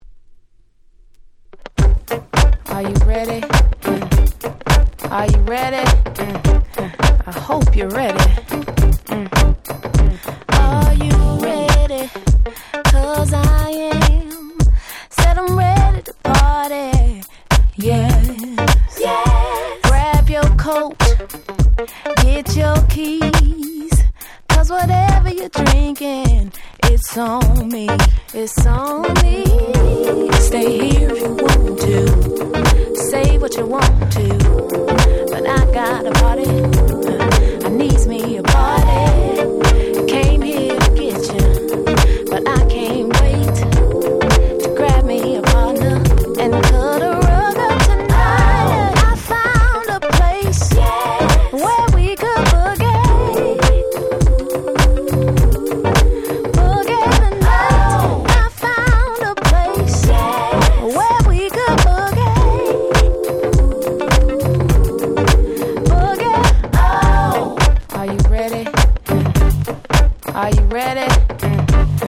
02' Smash Hit R&B !!
当時の空気感プンプンの気持ち良いフロアチューン！！
意外と美メロなサビ前のブリッジが最高！！